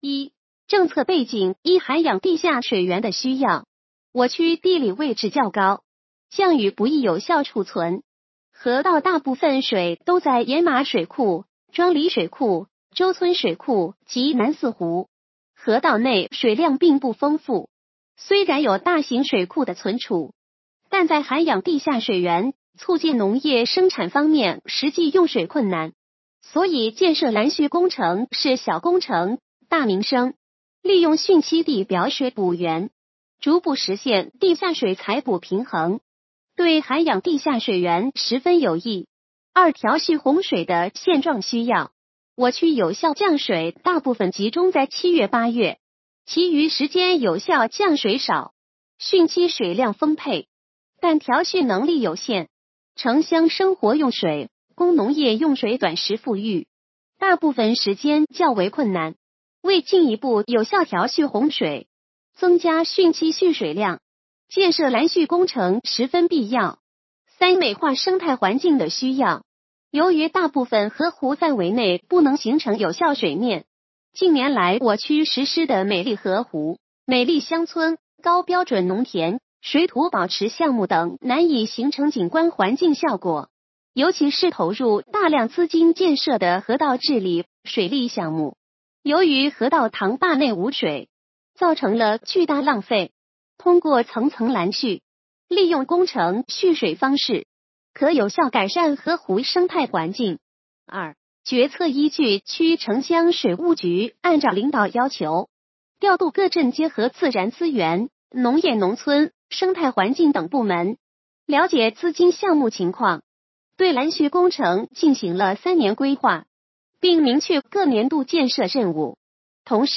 语音解读：山亭区人民政府办公室关于印发山亭区拦蓄工程建设的实施意见